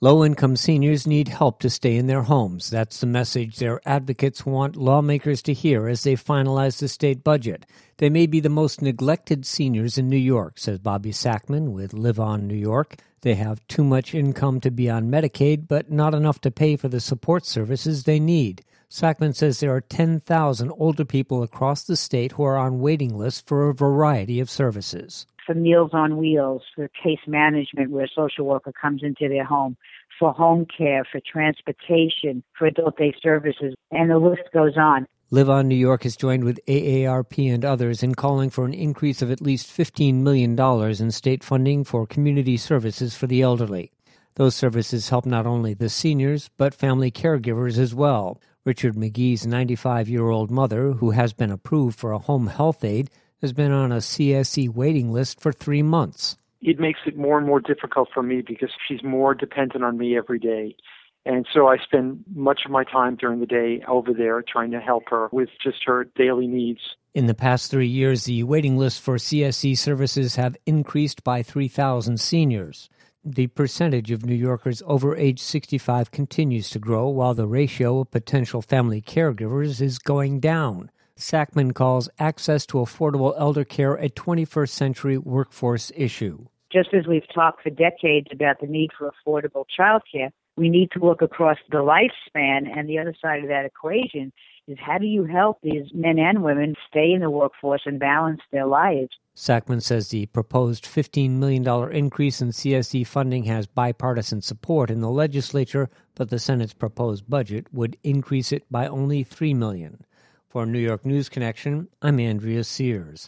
WGXC Evening News